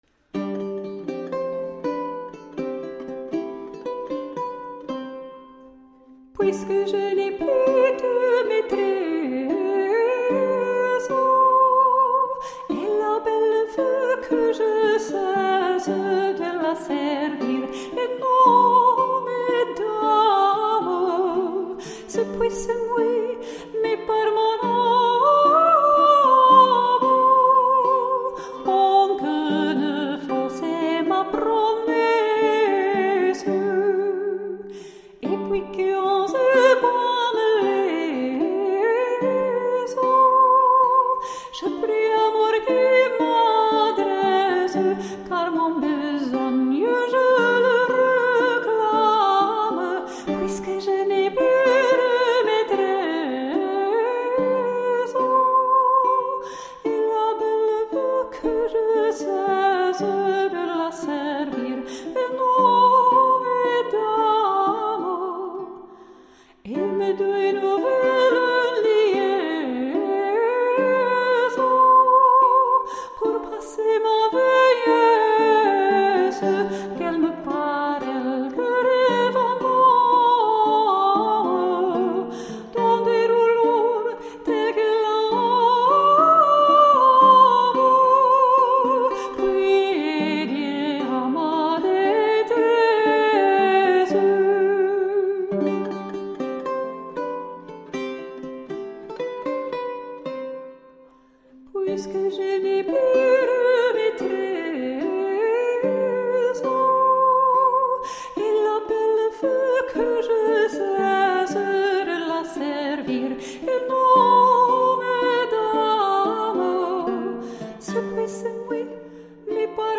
Late-medieval vocal and instrumental music